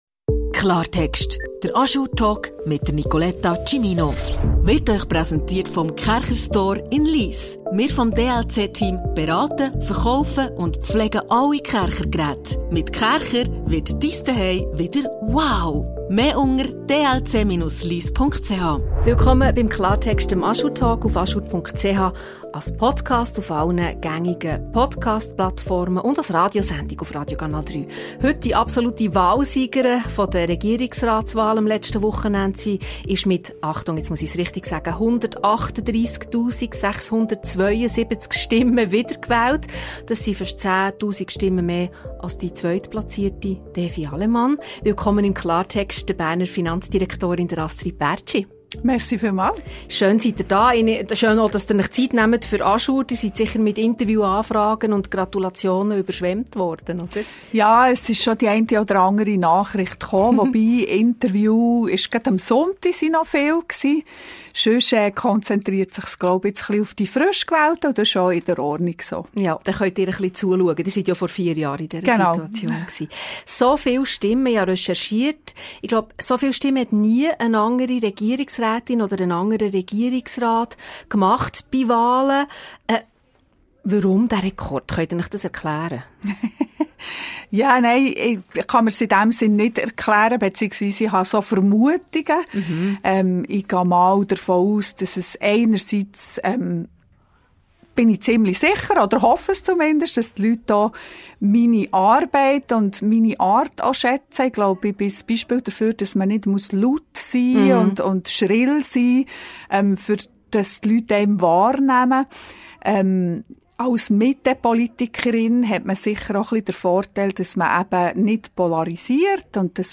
Inhaltlich will die Finanzdirektorin den eingeschlagenen Weg weitergehen: Steuern schrittweise senken, die Verwaltung digitalisieren und die Arbeitswelt in der Verwaltung modernisieren. Ein Gespräch über ihre glückliche Kindheit, das Reiten, die verpasste Karriere als Geigenspielerin – und warum sie ihrer Heimat Ostermundigen immer treu geblieben ist.